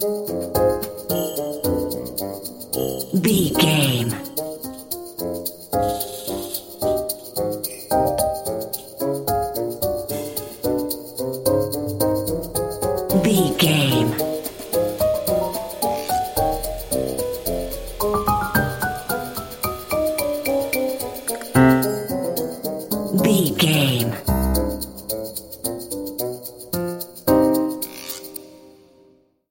Aeolian/Minor
percussion
flute
orchestra
piano
silly
circus
goofy
comical
cheerful
perky
Light hearted
secretive
quirky